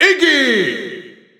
Announcer pronouncing Iggy in German.
Iggy_German_Announcer_SSBU.wav